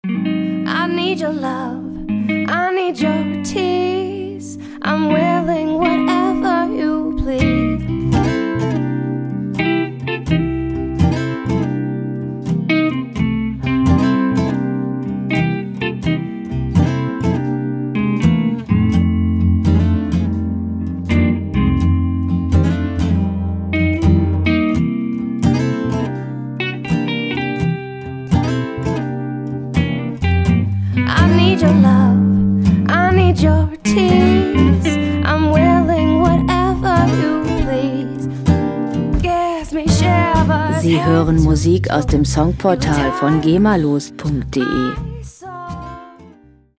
Lounge Musik - Cool und lässig
Musikstil: Blues
Tempo: 83 bpm
Tonart: C-Dur
Charakter: unaufgeregt, langsam